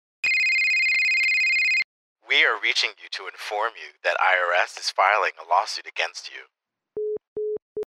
Phone call from robot
IRS_Scam_01.mp3